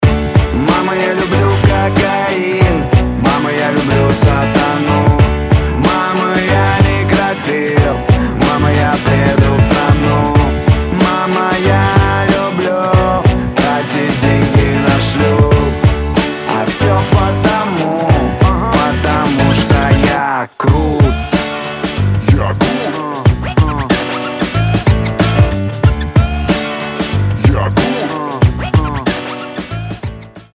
• Качество: 320, Stereo
Хип-хоп
alternative